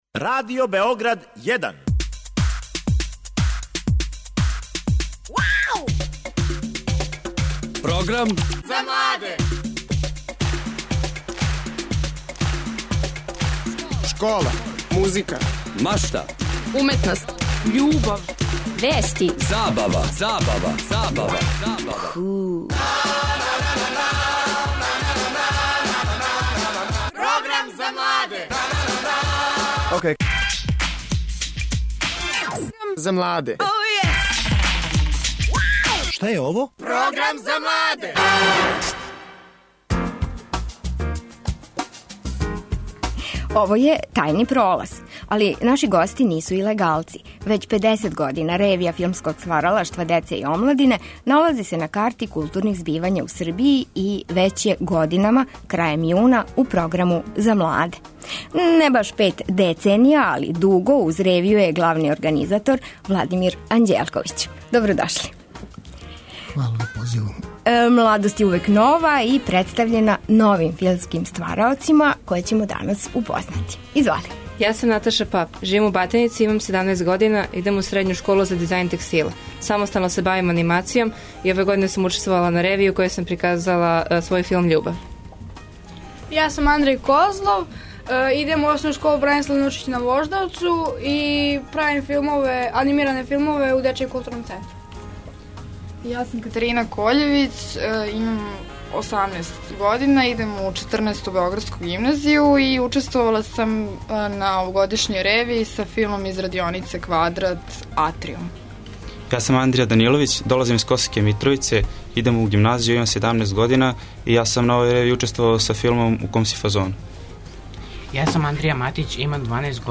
Наши гости биће неки од учесника ревије.